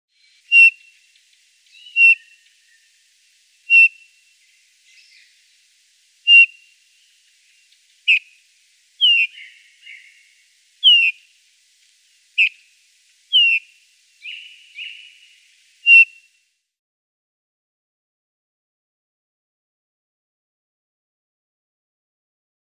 Le bouvreuil pivoine
Chant et cri
Peu visible à la belle saison, le Bouvreuil pivoine est plus facilement repérable grâce à son chant très simple : un bref sifflement doux et mélancolique, sur une seule note.
bouvreuil_pivoine_2.mp3